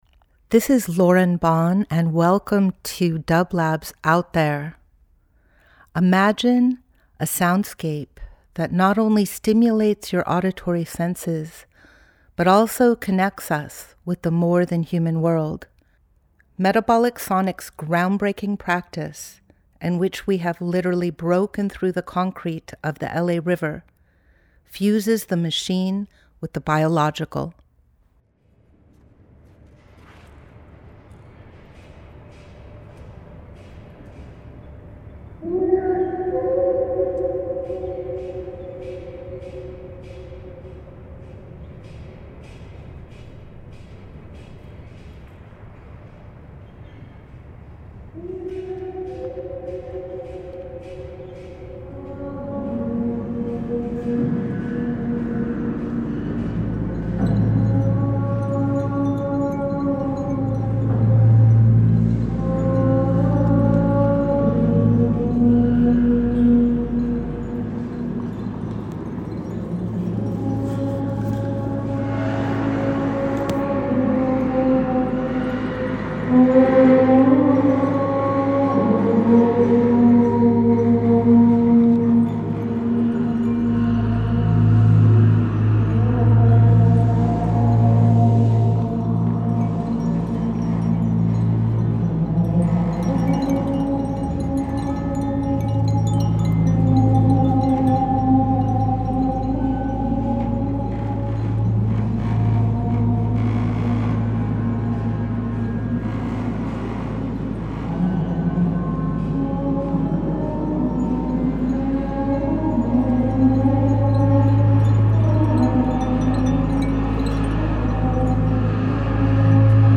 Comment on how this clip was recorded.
Each week we present a long-form field recording that will transport you through the power of sound. We had an international zoom jam between Buenos Aires, Los Angeles, and the silo in the Owens Valley.